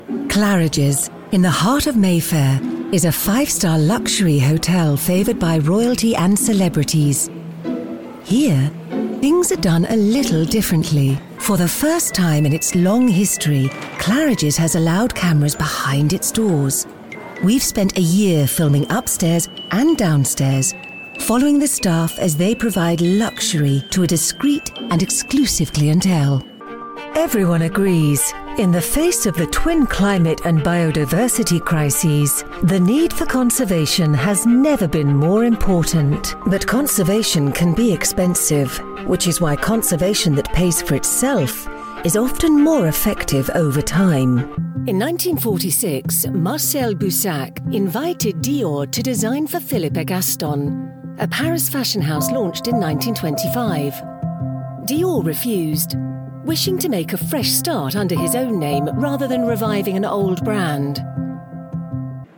Female
British English (Native)
Assured, Authoritative, Character, Corporate, Engaging, Friendly, Gravitas, Natural, Reassuring, Smooth, Warm, Versatile
Microphone: Neumann TLM 103
Audio equipment: Sound proof booth Scarlett 2i2 interface